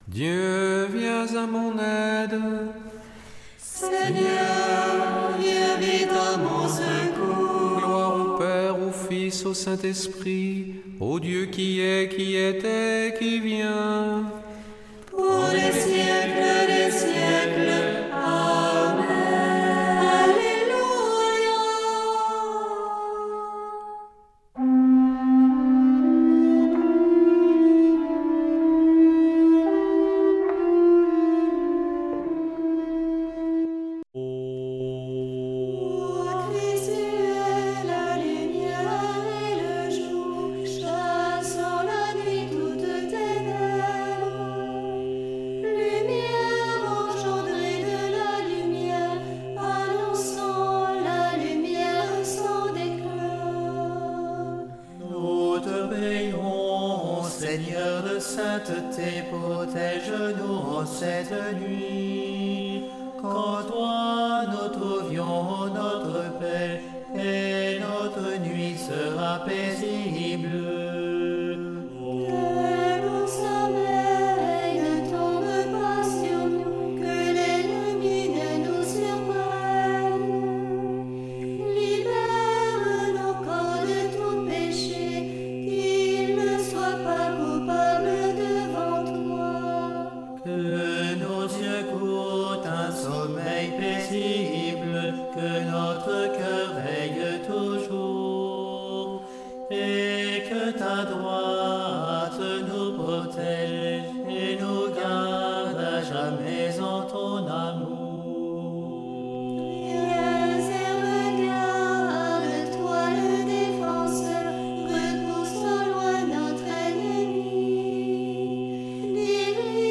Prière des complies - Temps ordinaire
Une émission présentée par Groupes de prière